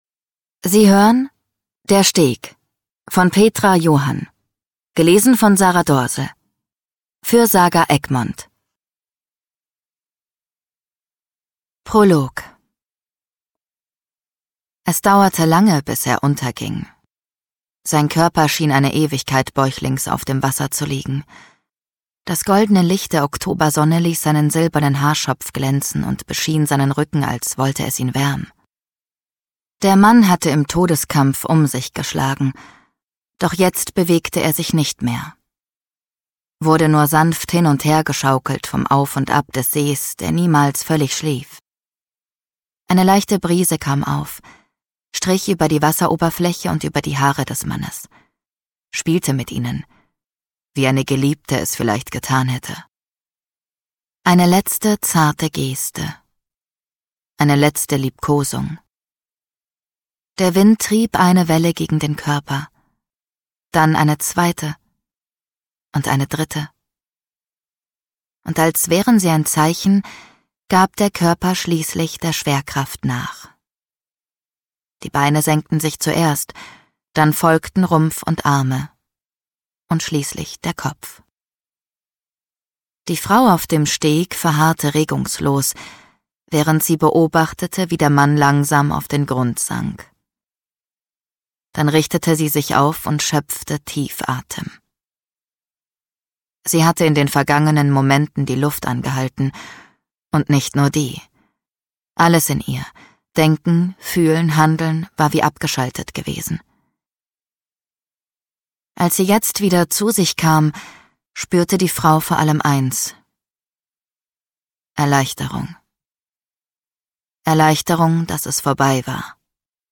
steinbach sprechende bücher | Hörbücher
Produktionsart: ungekürzt